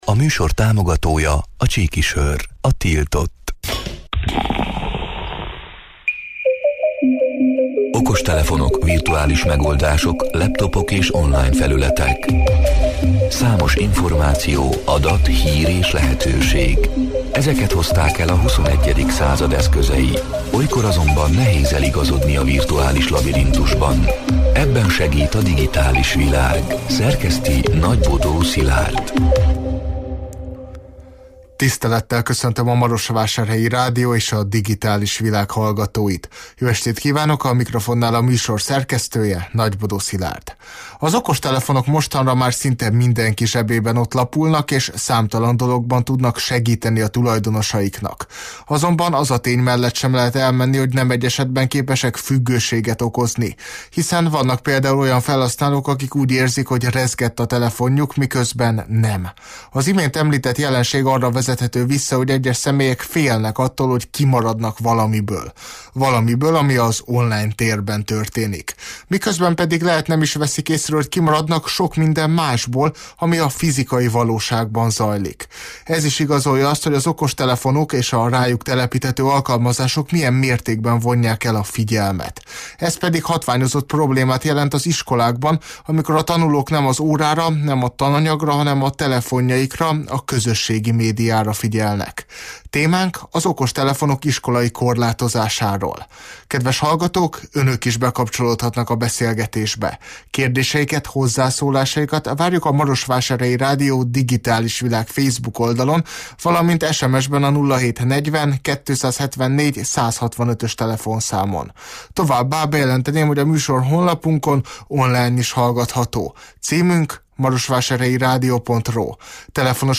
A Marosvásárhelyi Rádió Digitális Világ (elhangzott: 2024. augusztus 13-án, kedden este nyolc órától élőben) c. műsorának hanganyaga: Az okostelefonok mostanra már szinte mindenki zsebében ott lapulnak és számtalan dologban tudnak segíteni a tulajdonosaiknak.